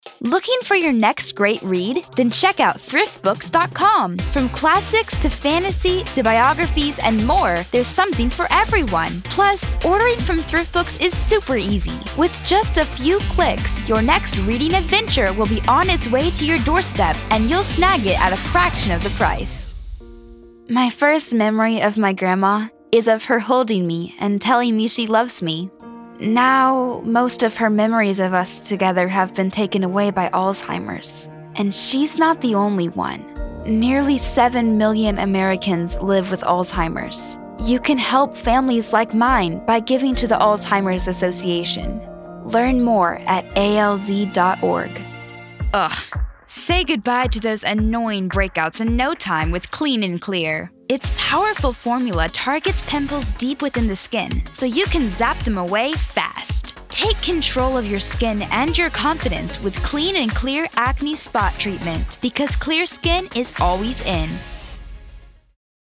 Sennheiser 416 microphone, acoustically-treated home studio